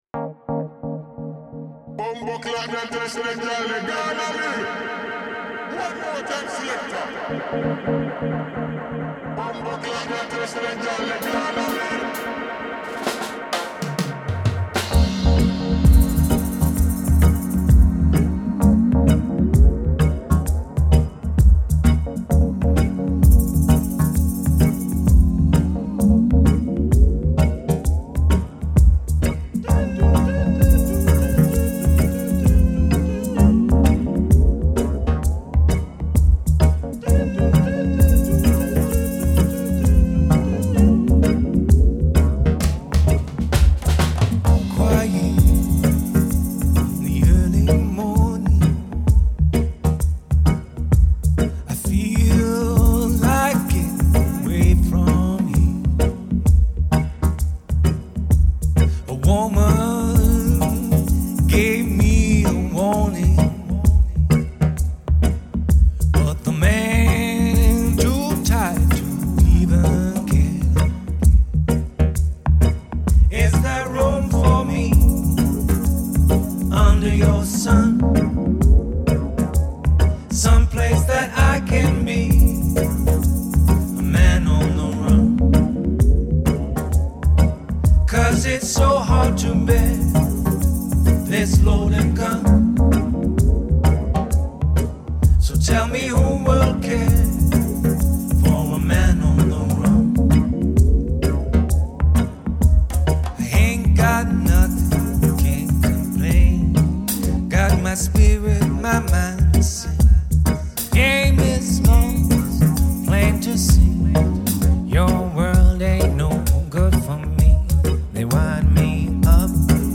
Genre: Dub Reggae